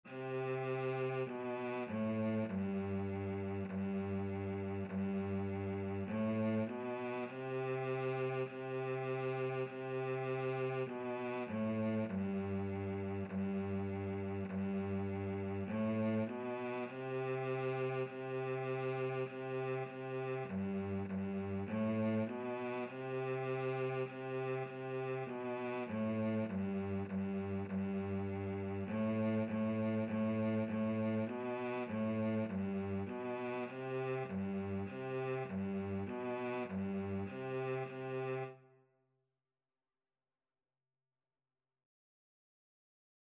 Cello version
4/4 (View more 4/4 Music)
G3-C4
Beginners Level: Recommended for Beginners
Instrument:
Cello  (View more Beginners Cello Music)
Classical (View more Classical Cello Music)